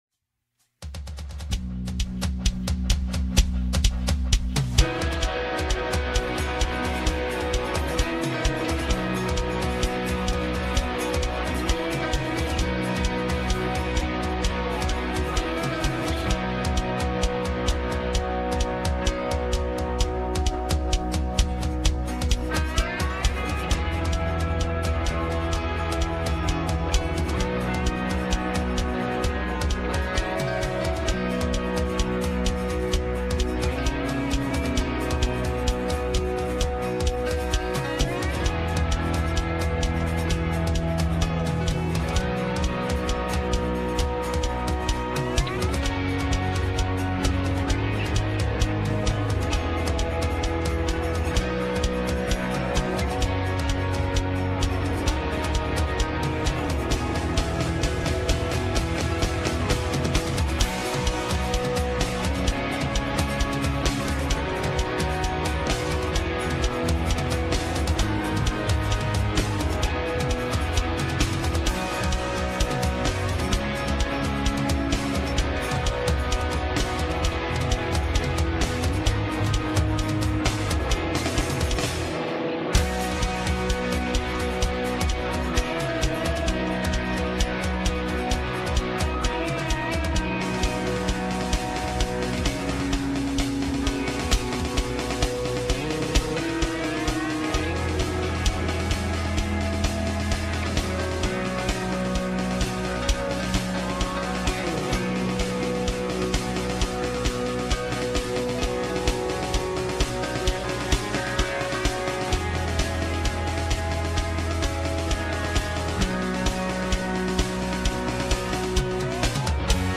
Westgate Chapel Sermons Resolute: Defining Success - Luke 10:1-20 Apr 13 2025 | 01:27:43 1x 00:00 / 01:27:43 Subscribe Share Apple Podcasts Overcast RSS Feed Share Link Embed